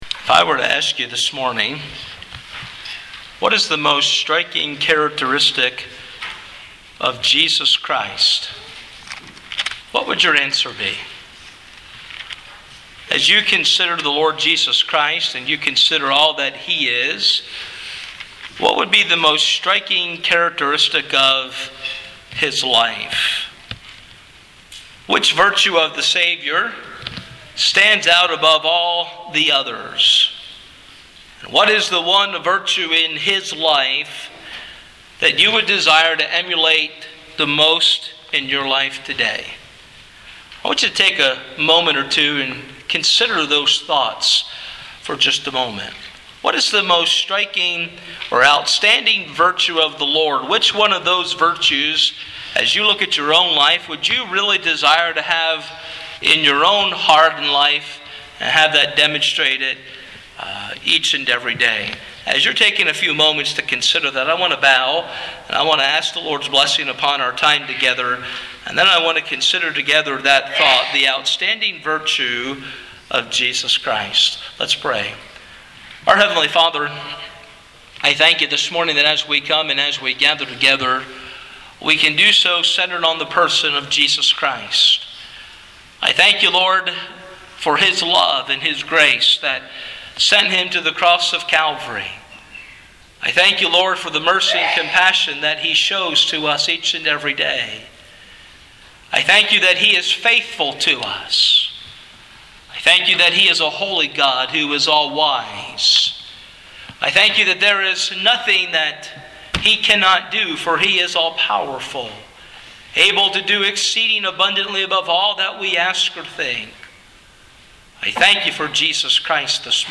Sermon MP3